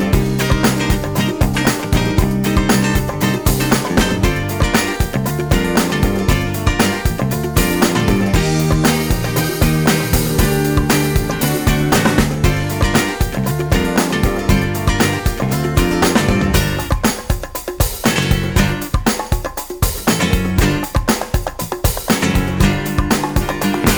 Minus Lead Guitar Pop (1970s) 3:25 Buy £1.50